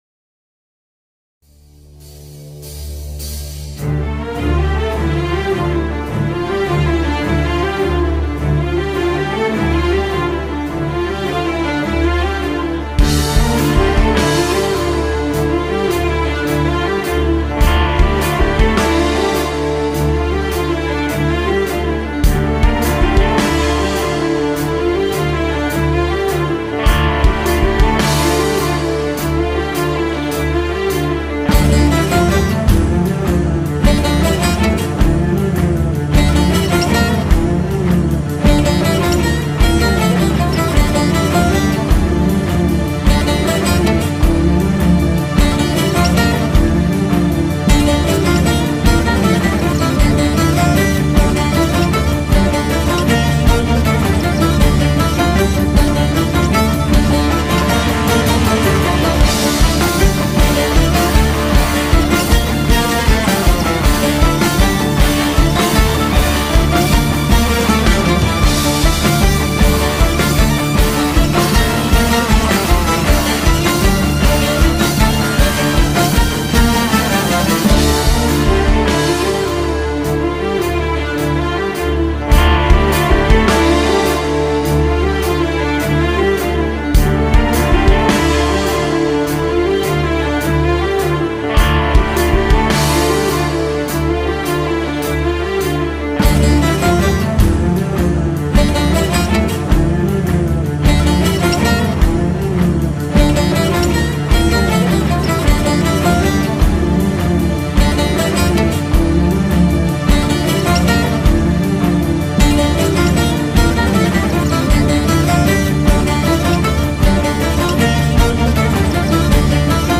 tema dizi müziği, heyecan gerilim aksiyon fon müzik.